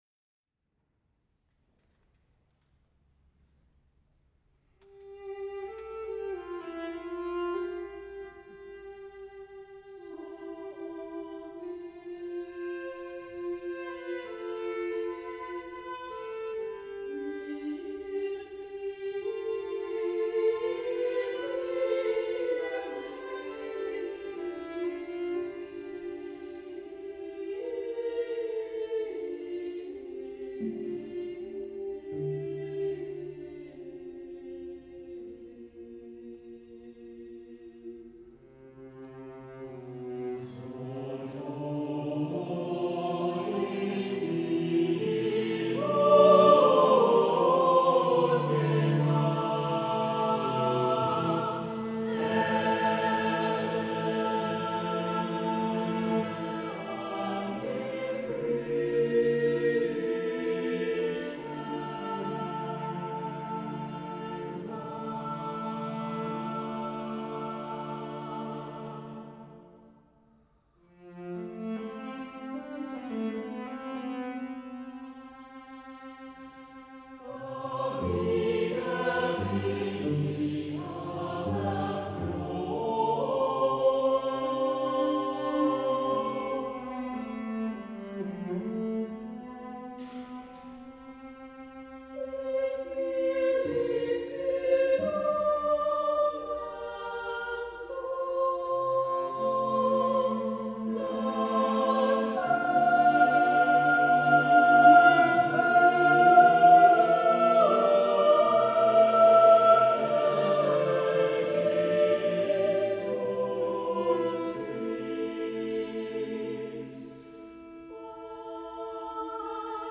Motette zu Karfreitag
für vierstimmig gemischen Chor und Violoncello solo, 6'10"
Violoncello